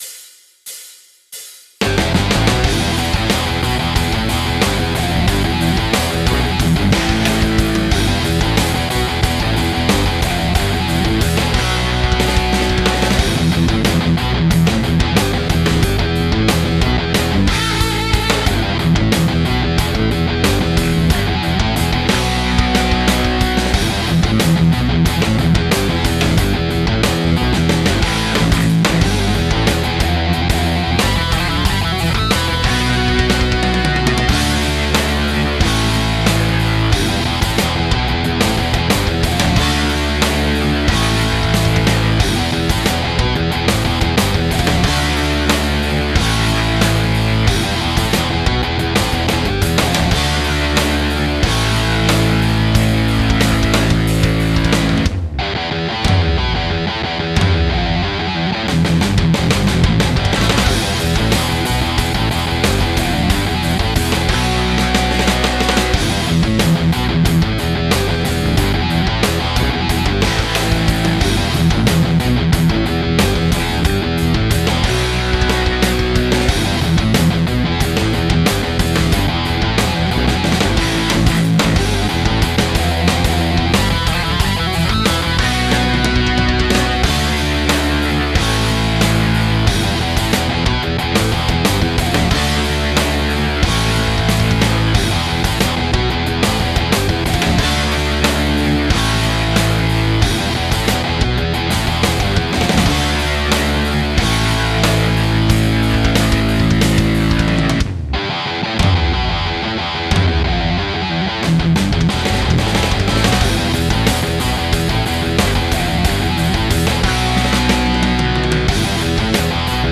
Voila un morceau trés rock sur lequel je bosse ,dites moi ce que vous en pensez.tout est programmé (batterie: DFHS ,basse:TRILOGY ,clavier:B4 et bien sur LPC pour la guitare):